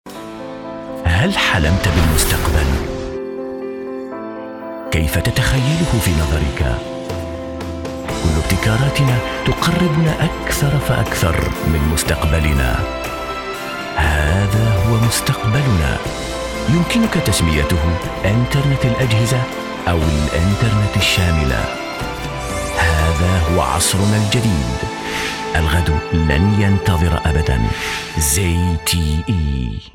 Kein Dialekt